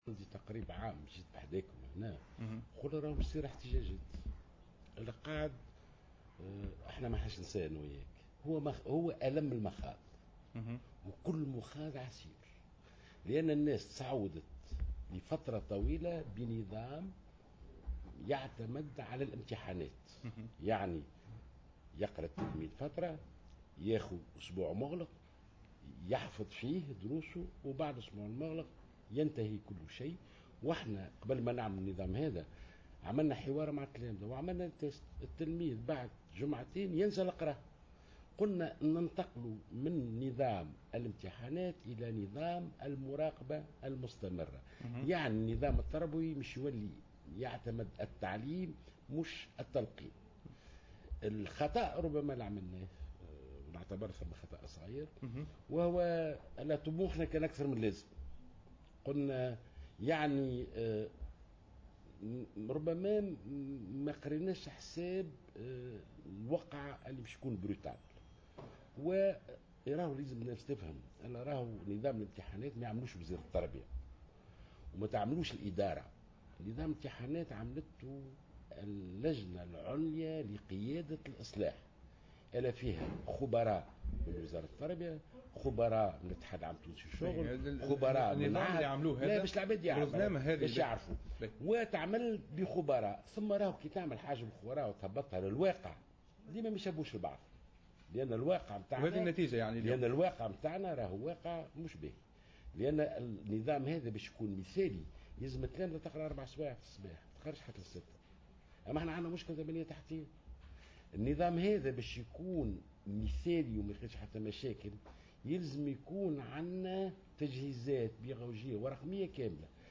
و أوضح جلول في حوار على القناة الوطنية الأولى " كل مخاض عسير..